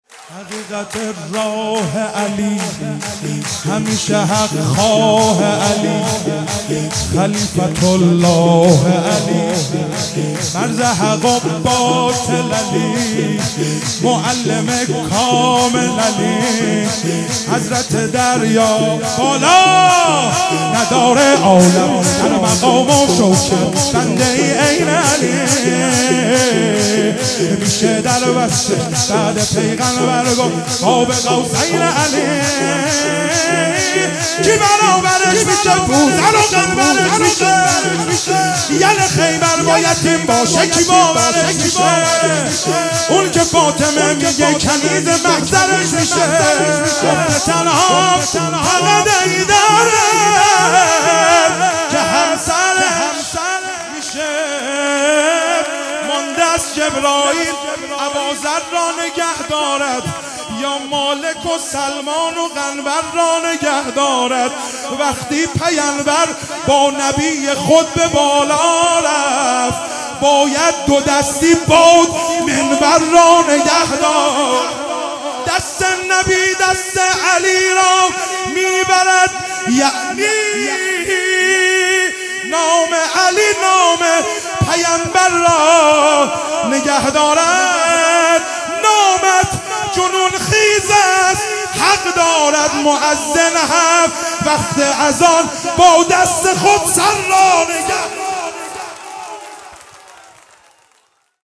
عید سعید غدیر خم